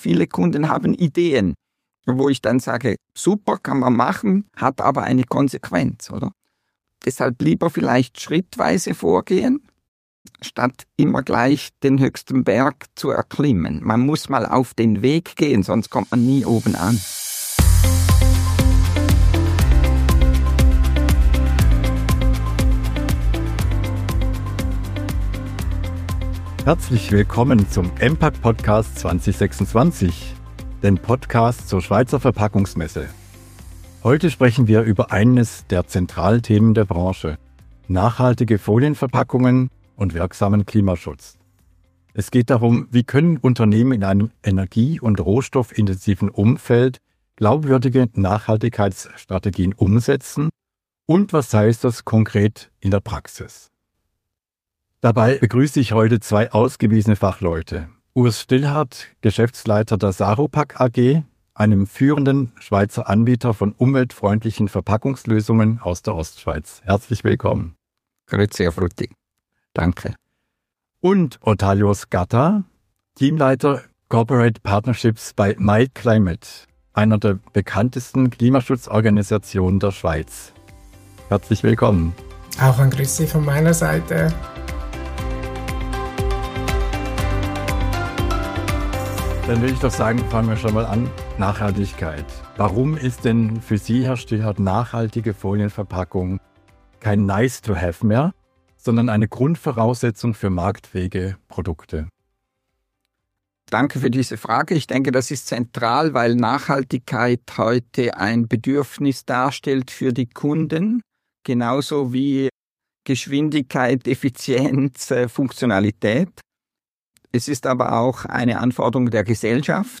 Sie erklären, warum Kundenerwartungen steigen, wo die grössten Hürden liegen und wie Saropack bereits heute messbare Klimawirkung erzielt. Ein praxisnahes Gespräch über Innovation, Verantwortung und die Rolle der EMPACK Schweiz 2026.